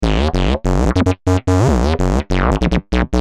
夜店常用的打碟转场音效
这是一款关于夜店常用的打碟转场音效，音效韵律感强，非常适合用于夜店，由本站提供，可直接下载。